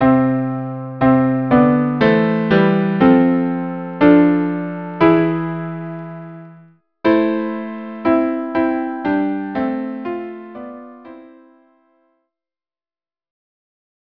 Instrumentation: Violin 1; Violin 2 or Viola; Cello